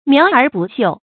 苗而不秀 注音： ㄇㄧㄠˊ ㄦˊ ㄅㄨˋ ㄒㄧㄨˋ 讀音讀法： 意思解釋： 指莊稼出了苗而沒有開花結果。